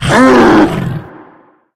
sounds / monsters / psysucker / hit_4.ogg
hit_4.ogg